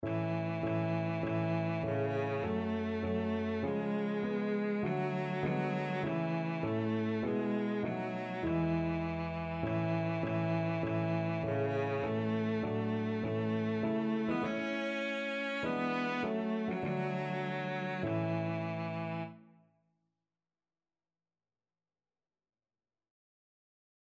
Christian Christian Cello Sheet Music Man of Sorrows - What a Name
Cello
A major (Sounding Pitch) (View more A major Music for Cello )
4/4 (View more 4/4 Music)
Classical (View more Classical Cello Music)